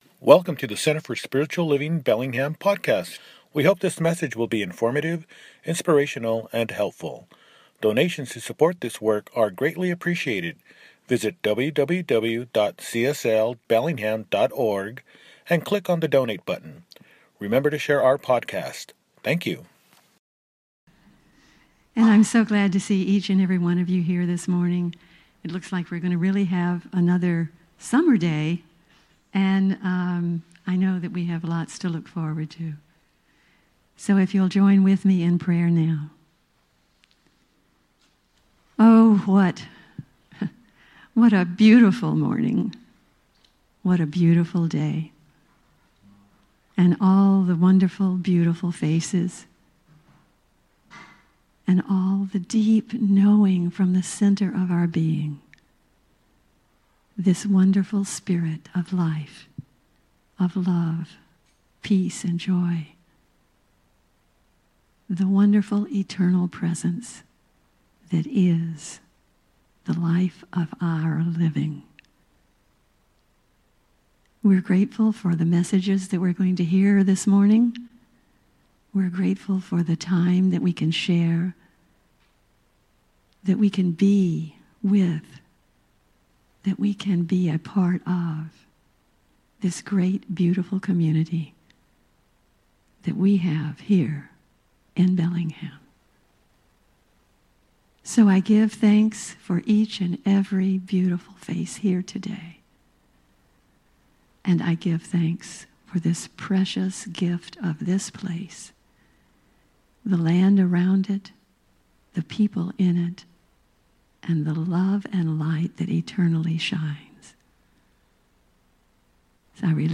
A Change of Scenery – Celebration Service